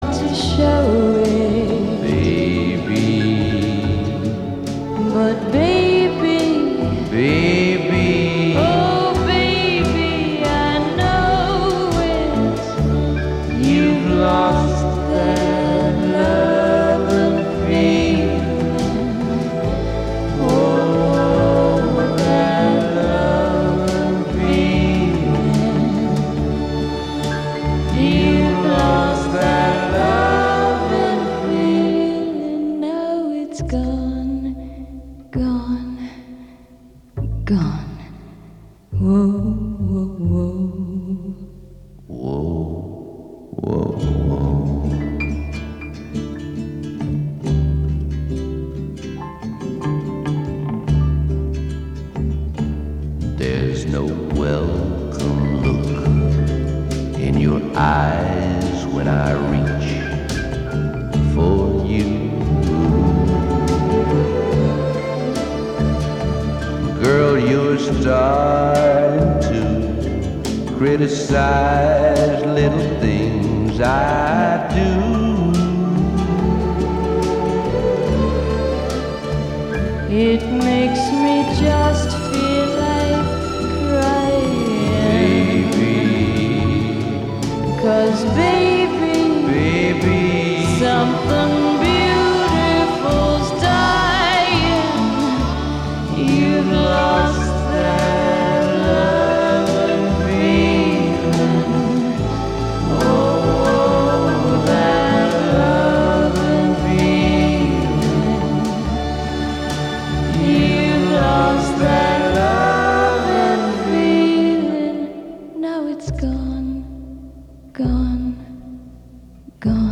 This weekly program of non-entropic thought sounds like what happens if you don't go to the dentist. Broadcast live from somewhere in Hudson, New York on WGXC (90.7-FM).